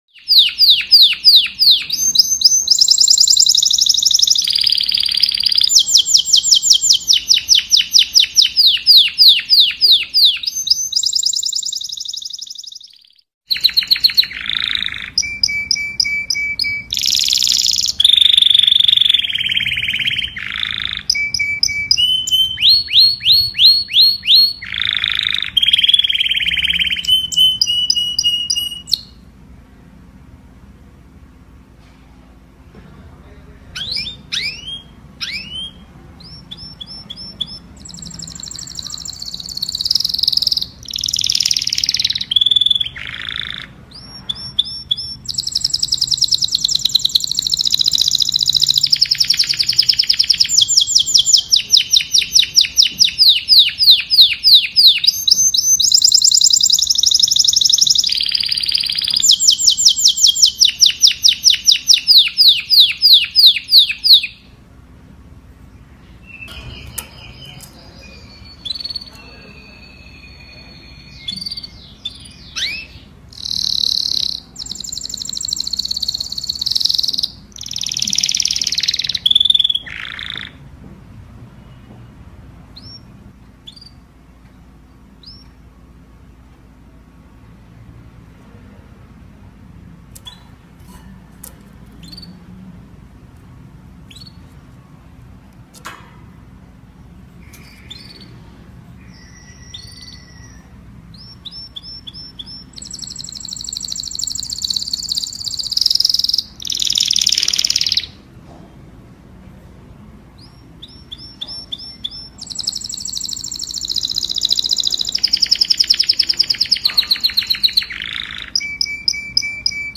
Download masteran suara Burung Kenari Gacor mp3 berkualitas tinggi, bebas dari gangguan suara latar belakang dan iklan, melalui tautan ini.
Suara Burung Kenari
Tag: suara burung hias suara burung Kenari suara kicau burung
Berikut ini adalah masteran suara gacor terbaik dari Burung Kenari yang berkualitas tinggi, tanpa gangguan suara latar atau iklan.
suara-burung-kenari-id-www_tiengdong_com.mp3